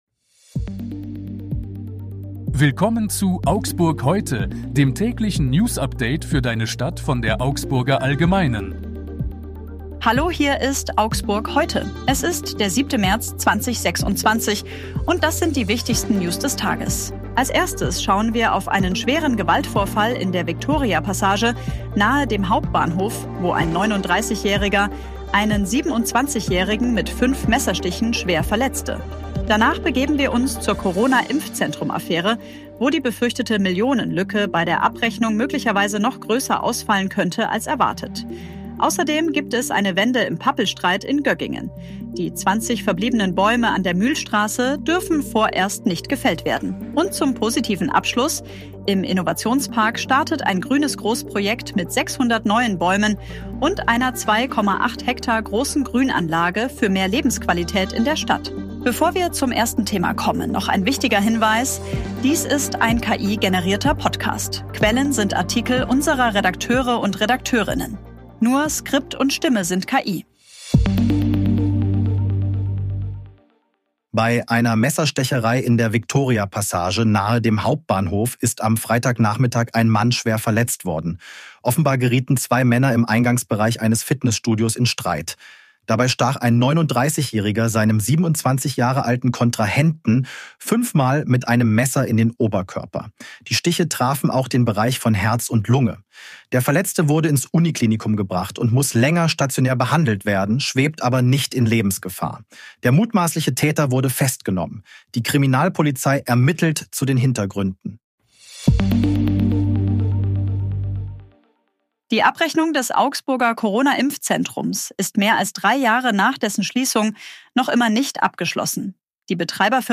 Nur Skript und Stimme sind KI.